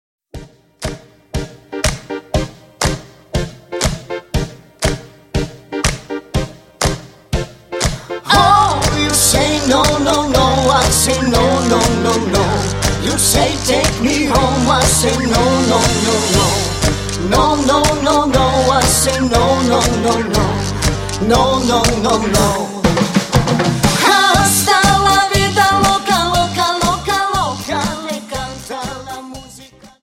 Dance: Cha Cha